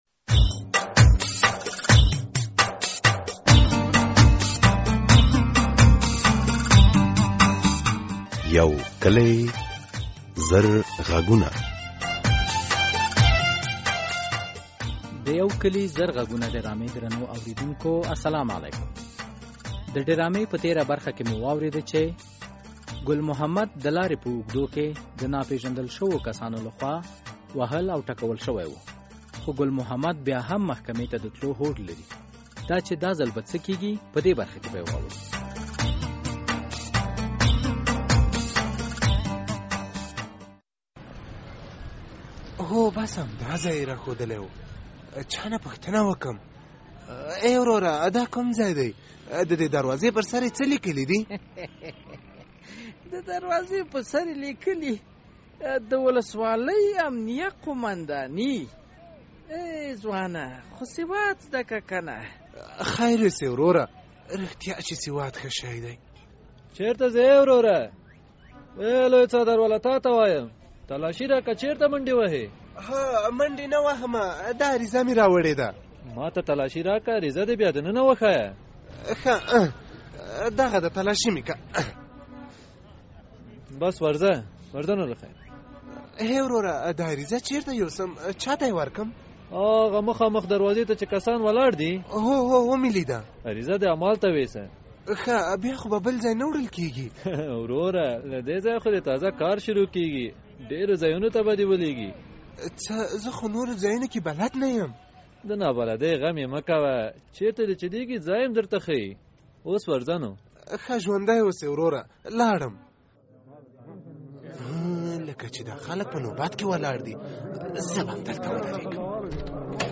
یو کلي او زر غږونه ډرامه هره اوونۍ د دوشنبې په ورځ څلور نیمې بجې له ازادي راډیو خپریږي.